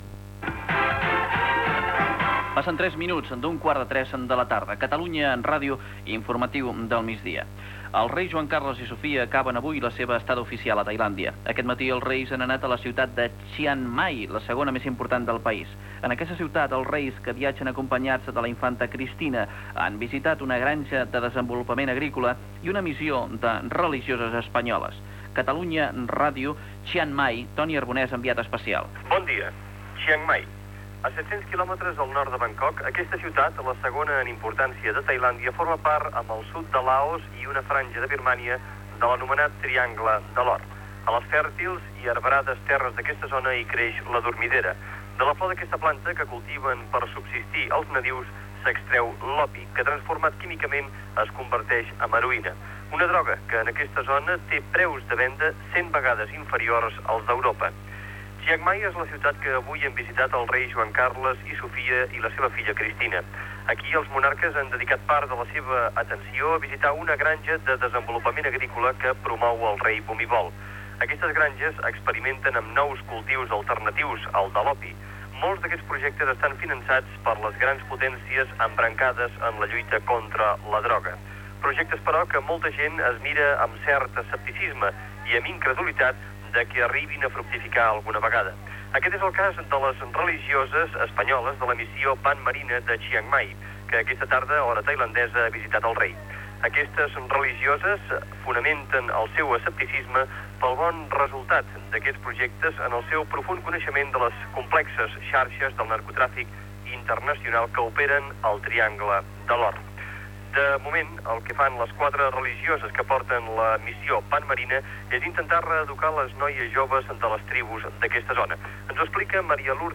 Sintonia de la ràdio, hora, informació, des de Bangkok, del quart dia de la visita dels reis d'Espanya Juan Carlos I i Sofia a Tailàndia
Informatiu